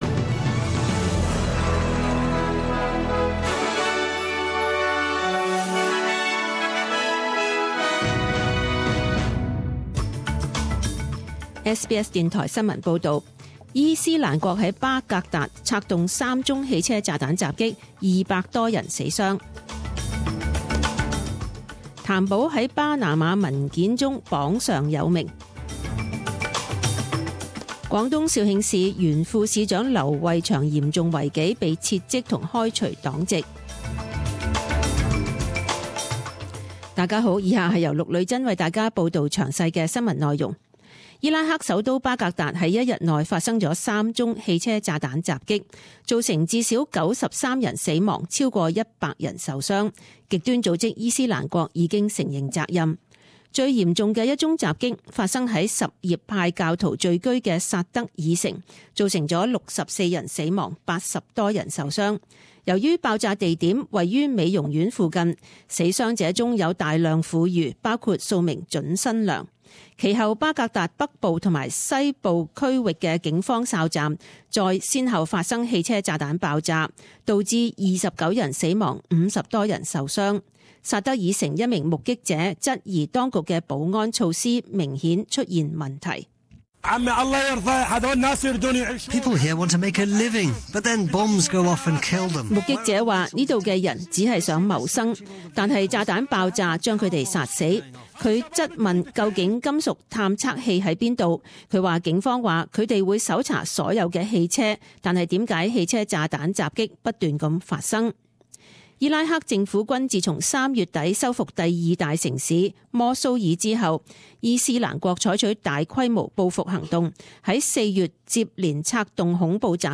十點鐘新聞報導（五月十二日)